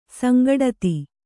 ♪ sangaḍati